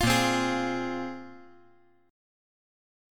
Listen to Bb7sus2 strummed